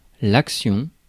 Ääntäminen
IPA: [ak.sjɔ̃]